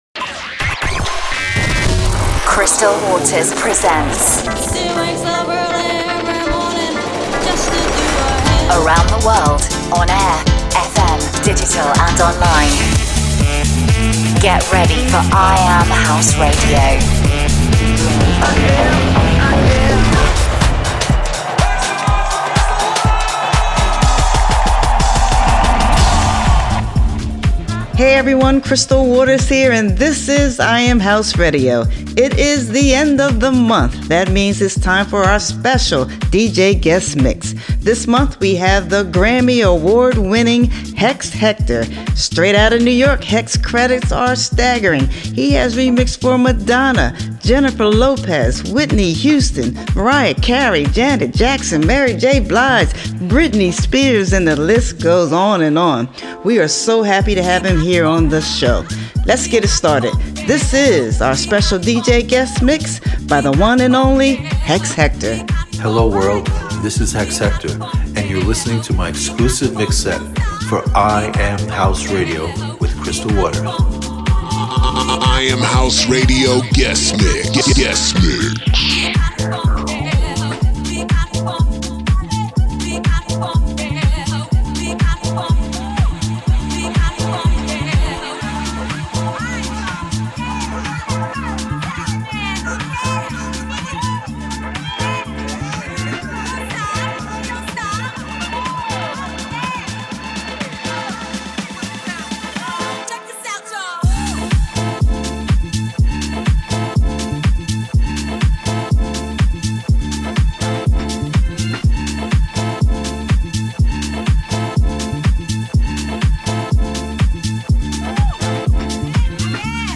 Playing the best new House Music from around the world.